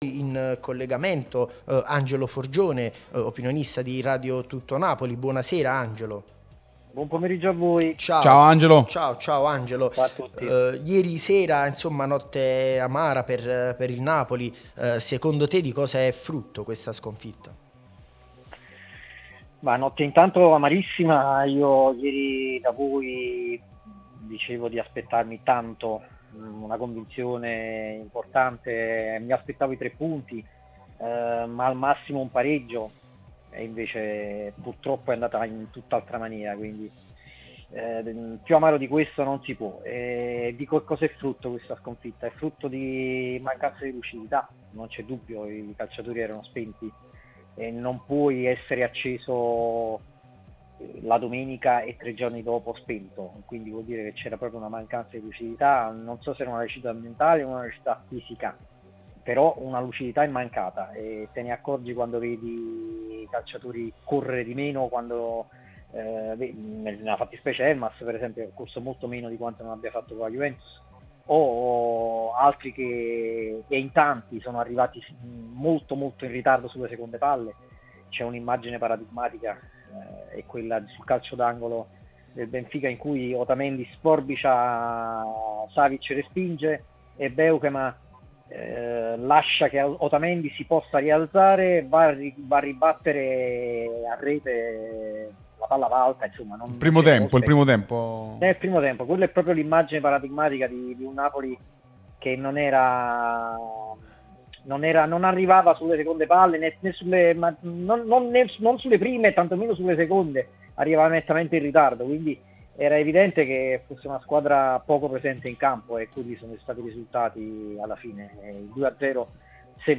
Radio TN